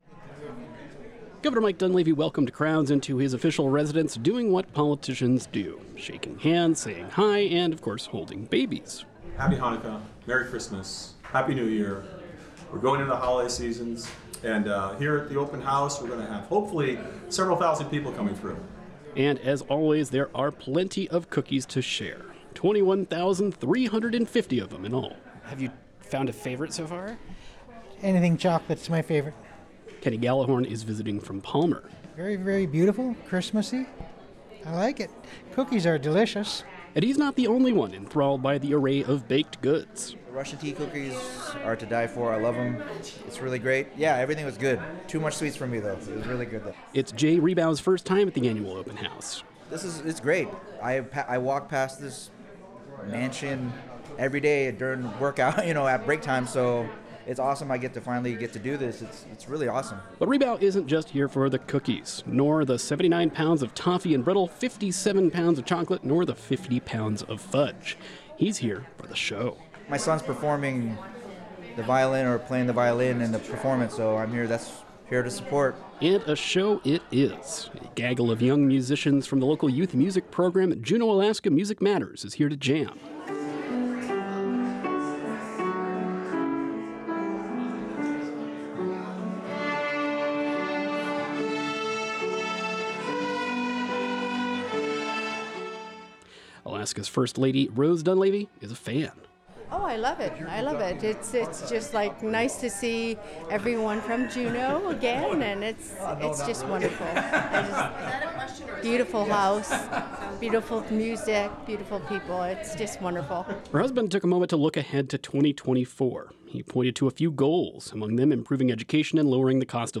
The members from Juneau Alaska Music Matters, an organization for local youth performs on the Governor’s Mansion during a holiday open house on December. 12 2023.